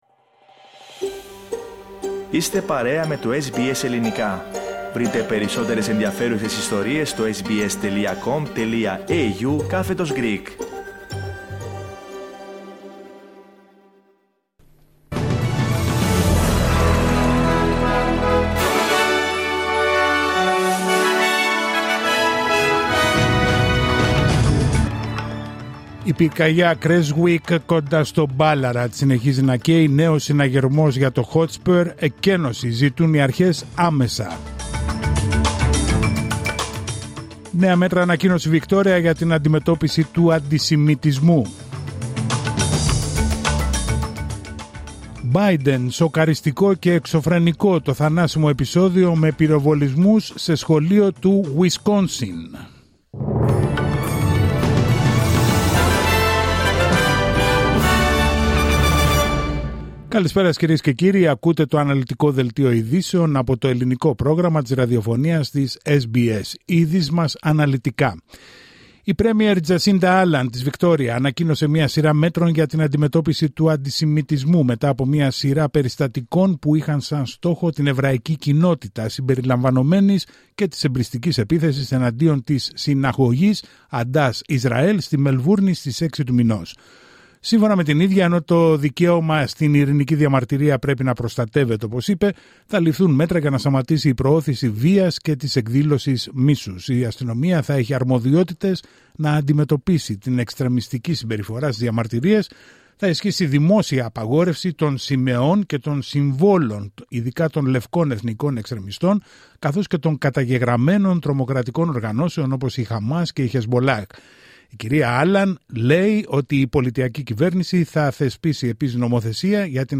Δελτίο ειδήσεων Τρίτη 17 Δεκεμβρίου 2024